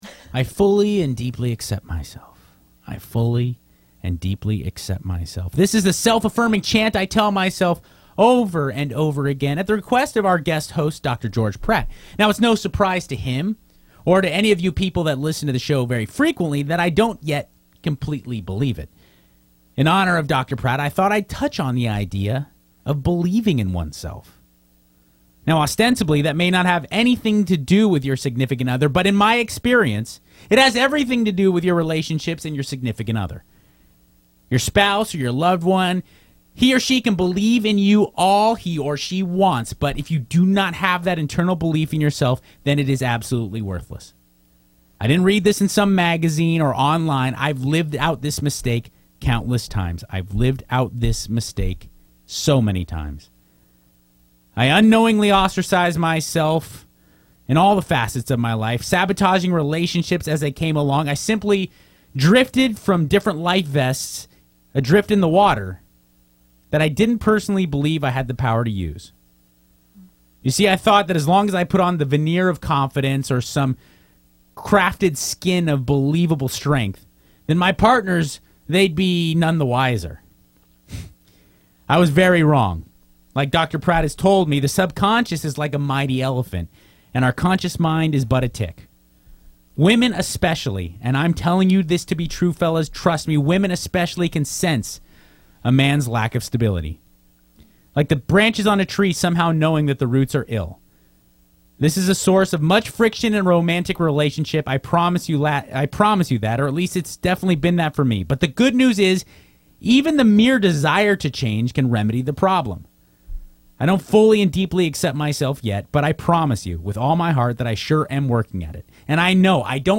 Loveline is a call-in question-and-answer radio show with the primary goal of helping youth and young adults with relationship, sexuality, and drug addiction problems.